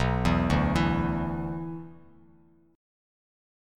Bmbb5 chord